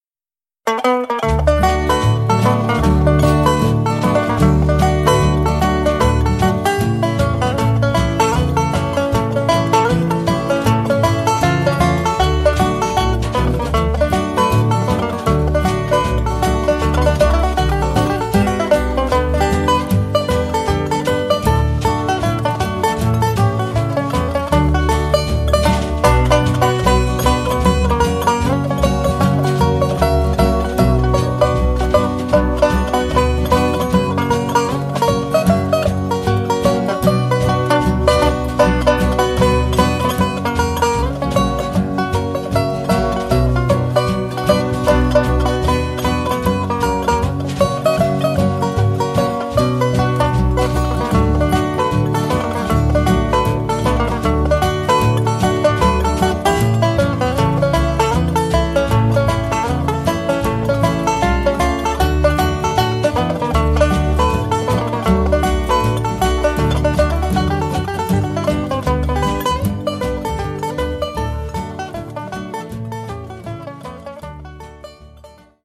5-string banjo